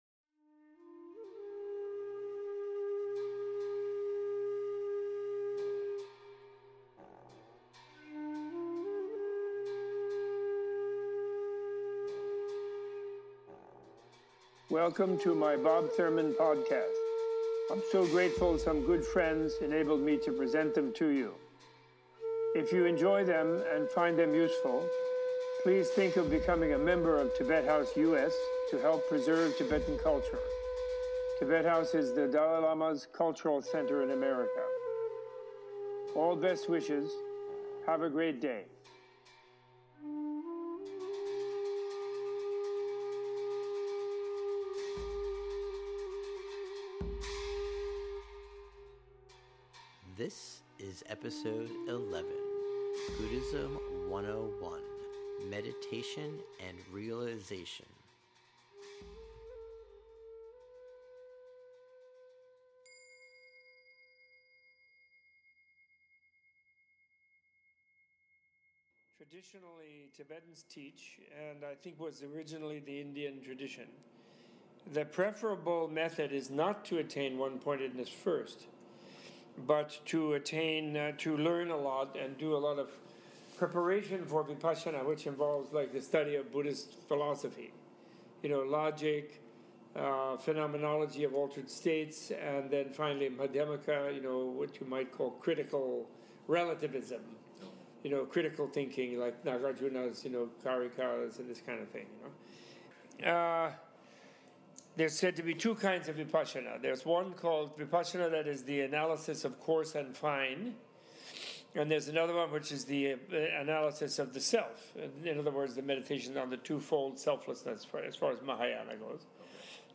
Professor Thurman speaks one on one to a student about meditation practices, quiescence (pali: samatha, sanskrit: śhamatha), insight (pali: vipassanā, sanskrit: vipaśhyanā) and meditative realization (bhāvanā). Includes a discussion of the common misunderstandings of the three, and how to use them on the path to enlightenment.